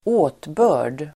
Ladda ner uttalet
åtbörd substantiv, gesture , motion Uttal: [²'å:tbö:r_d] Böjningar: åtbörden, åtbörder Definition: tecken med kroppen, gest Exempel: hon sträckte upp händerna i en förtvivlad åtbörd (she raised her hands in a gesture of despair)